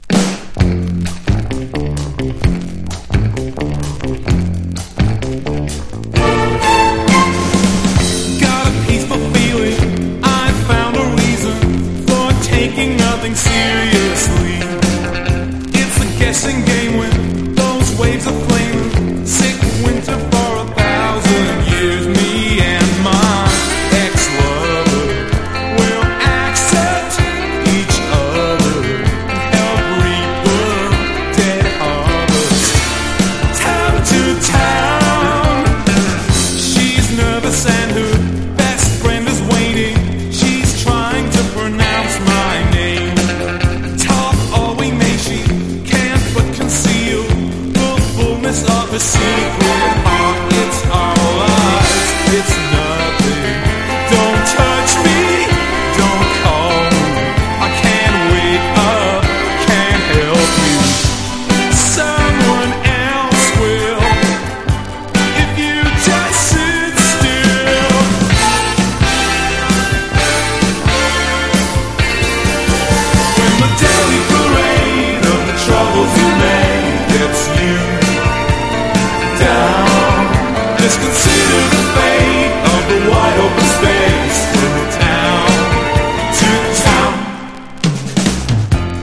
1. NEW WAVE >